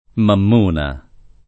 [ mamm 1 na ]